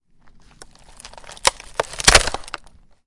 木栅栏的声音 " 啪啪, 木栅栏, L
Tag: 栅栏 开裂 裂缝 开裂 断裂 木材 木方 木板 栅栏 折断 开裂 捕捉 围栏 围栏 打破